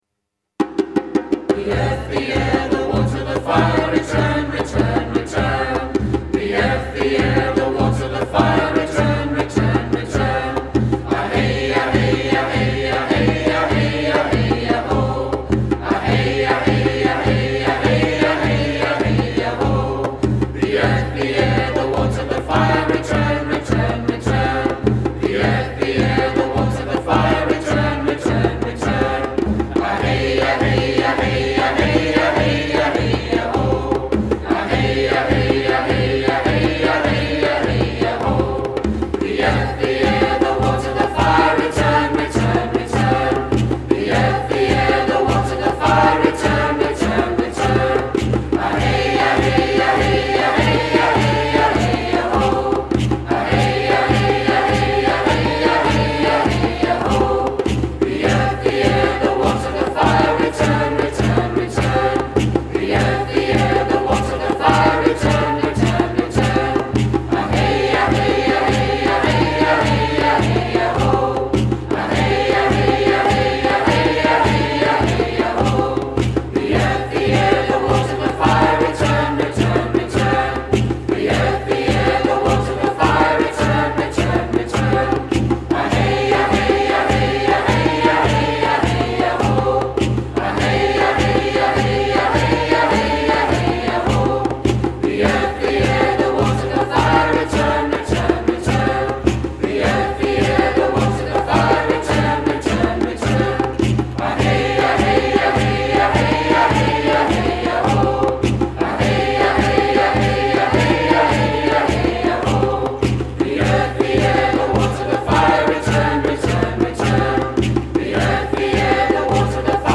(Native American)